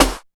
59 SNARE.wav